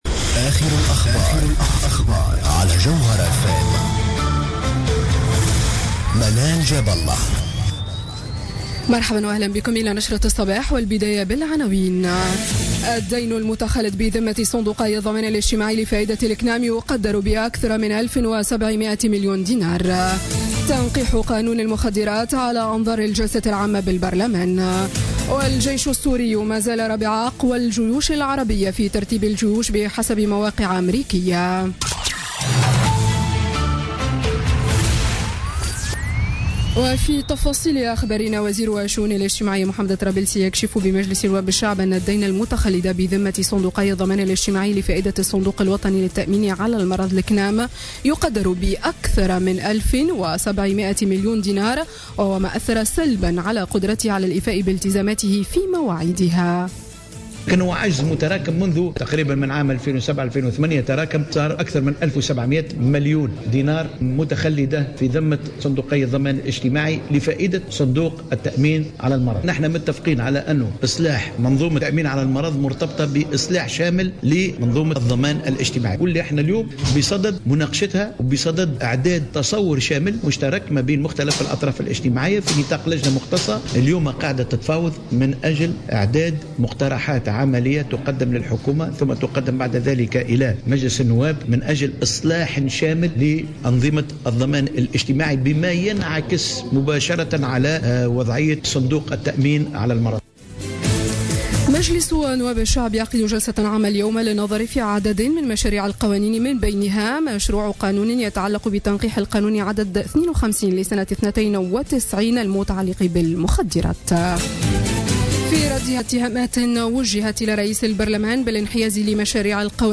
نشرة أخبار السابعة صباحا ليوم الثلاثاء 25 أفريل 2017